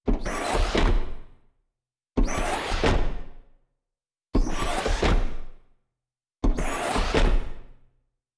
SFX_RoboSteps_Squeaky_03.wav